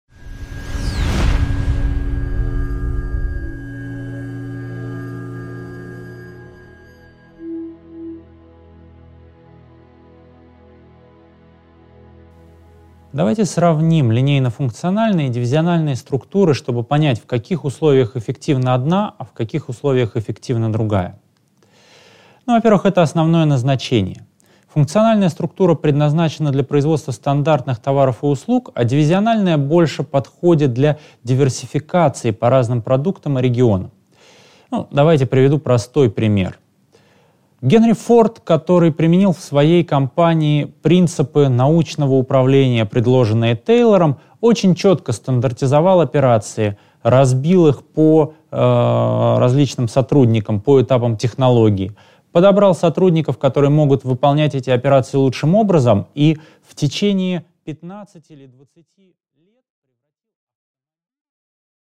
Аудиокнига 2.6. Сравнение функциональной и дивизиональной структур | Библиотека аудиокниг